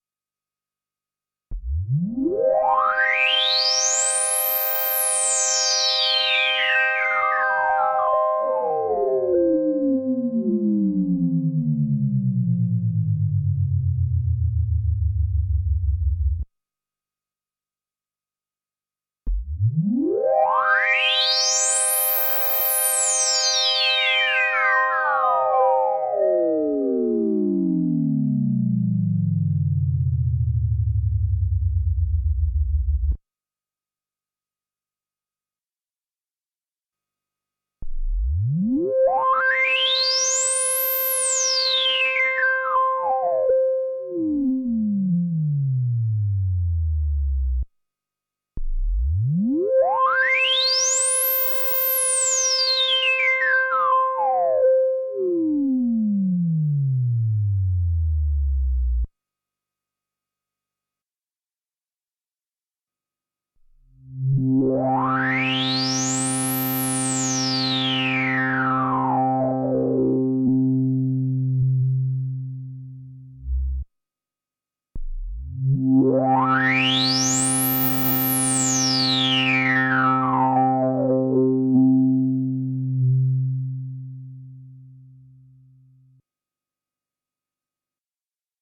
TAL-U-NO-LX is a synthesizer plug-in that faithfully recreates the iconic sound of the analog Juno 60 synth.
Following wave file compares the sound of the 30 years old hardware to the emulation. The hardware plays first:
juno_vs_u-no_v2.mp3